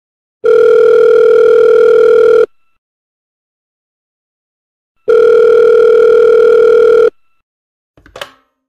Phone ring and pull up (1)